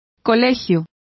Complete with pronunciation of the translation of institute.